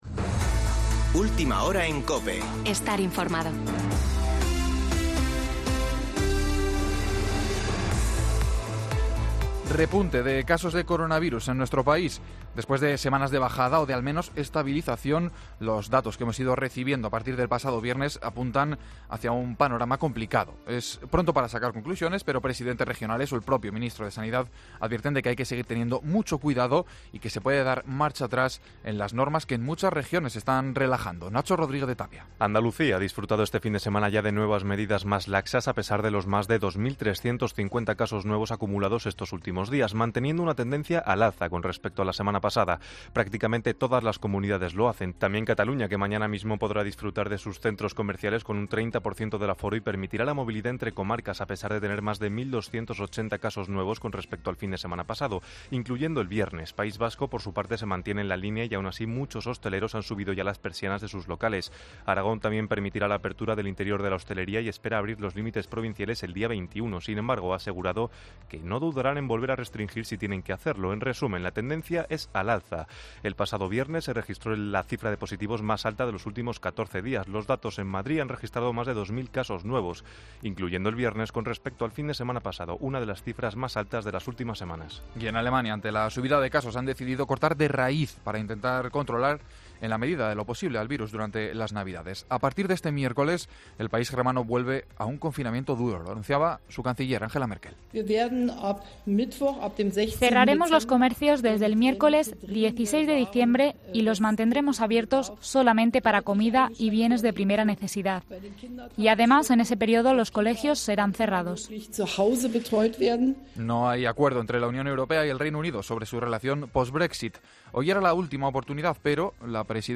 Boletín de noticias COPE del 13 de diciembre de 2020 a las 19.00 horas